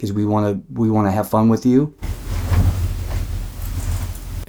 EVP Clip 1 Villisca Axe Murder House EVP Clip 1 This EVP was captured on our Zoom H4N recorder in the upstairs kids' room, where the four Moore children were killed. I had been doing a spirit box session using our P-SB7 device, but then decided to pause and to a straight EVP session. I finished telling the children that we just wanted to have fun with them, and immediately after I spoke a male whisper-voice was recorded giving what I believe is a very disturbing and chilling answer.